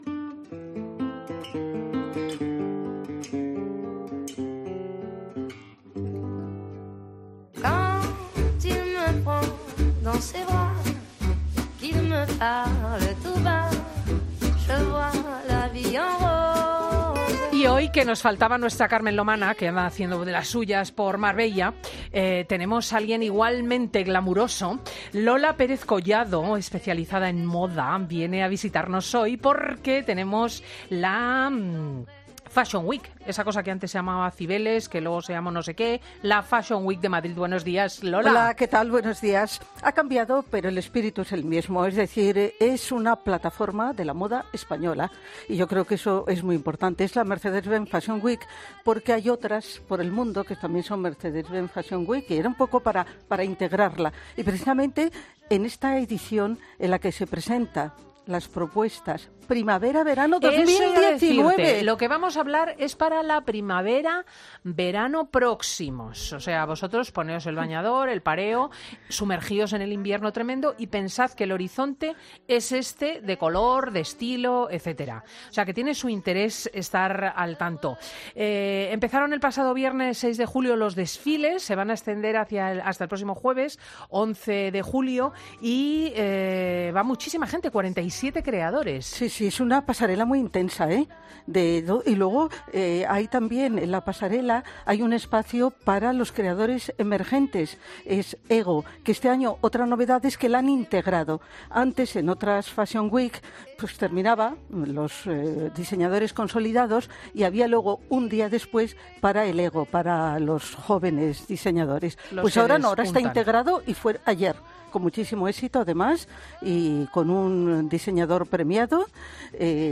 Escucha la entrevista completa en Fin de Semana con Cristina López Schlichting para no perderte ningún detalle.